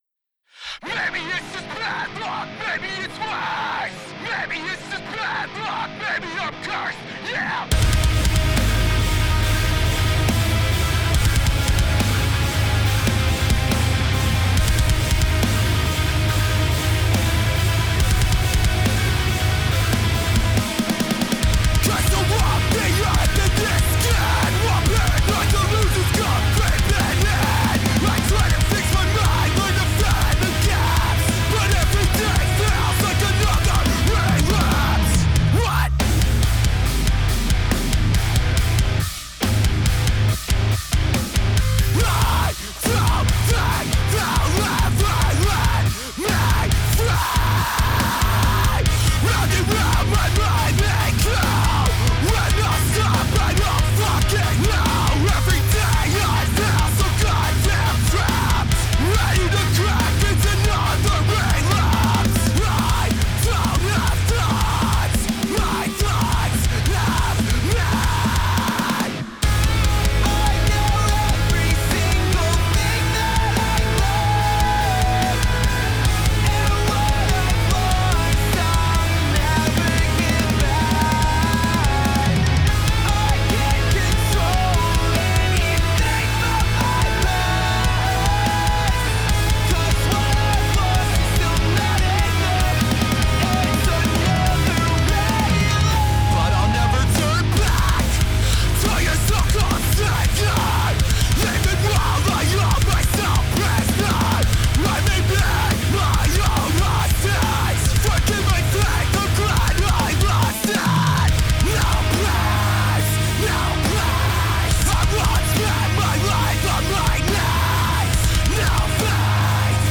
Reamping, Mixing, Mastering